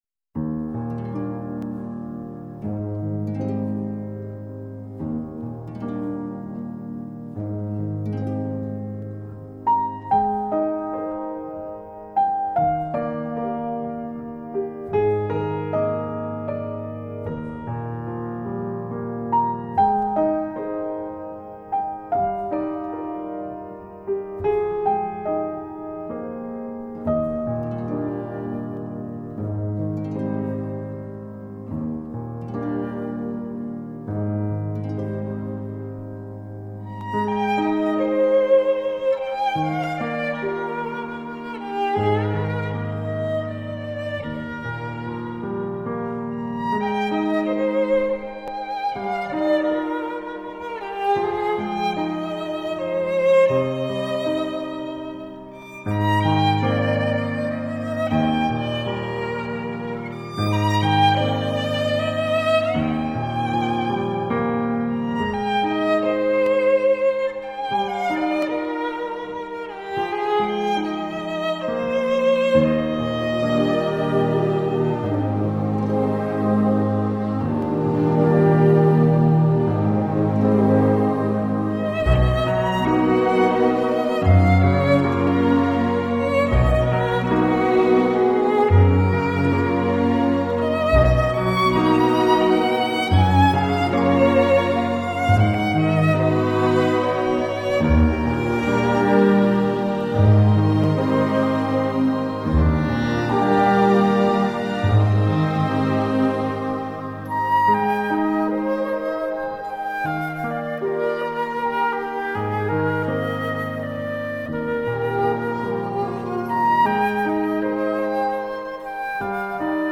• 背景音乐（适合一天到晚放个不停的音乐）.mp3
背景音乐（适合一天到晚放个不停的音乐）.mp3